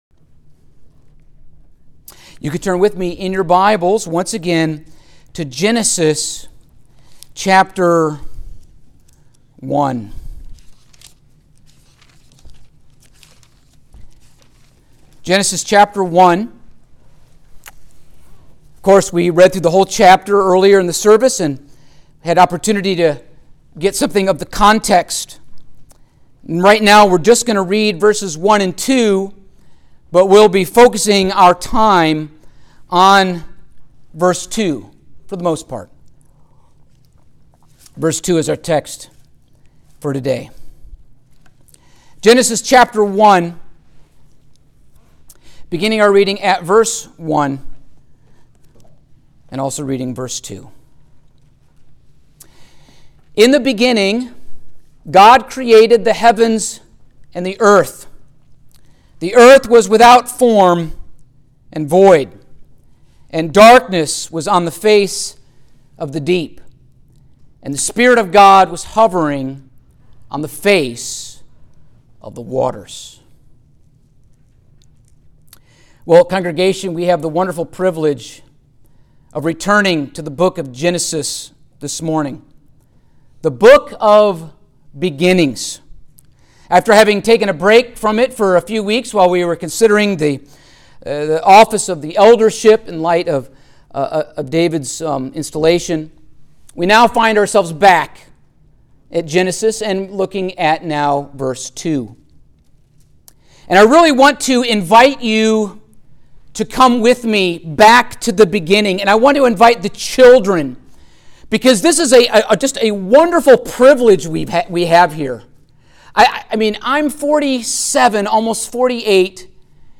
Genesis 1:2 Service Type: Sunday Morning Topics